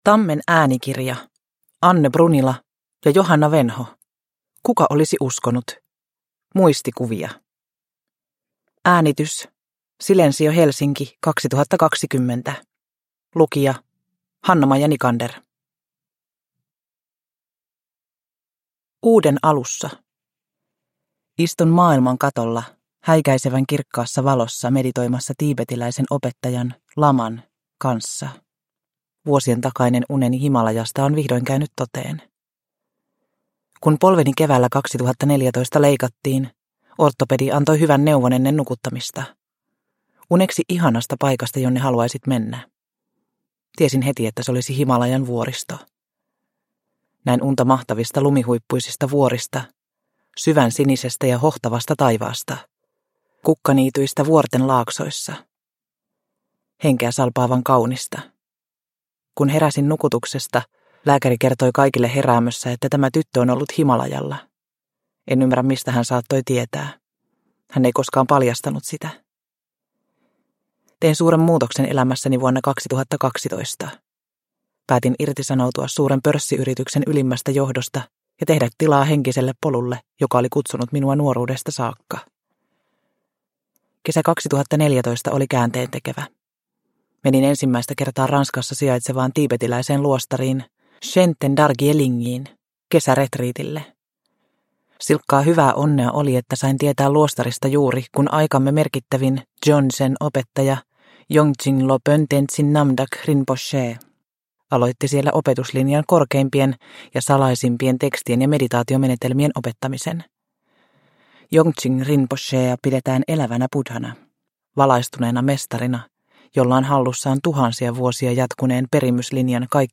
Kuka olisi uskonut – Ljudbok – Laddas ner